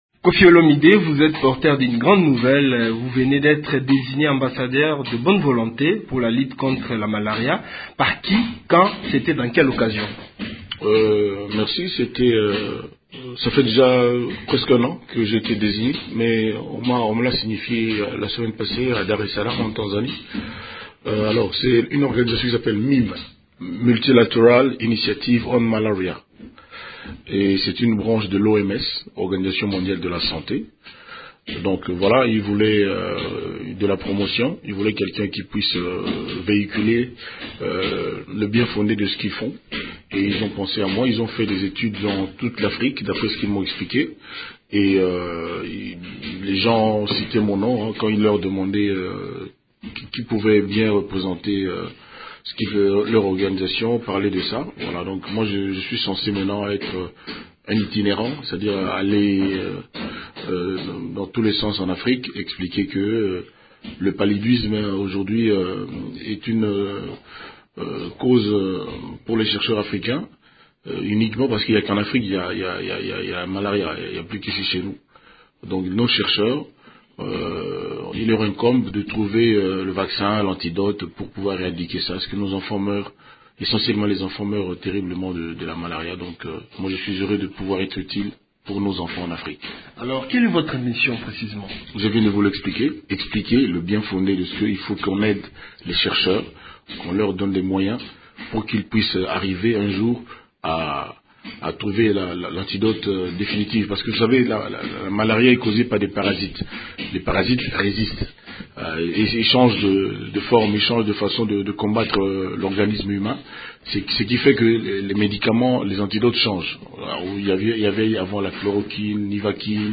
Koffi Olomide parle de sa nouvelle mission au Micro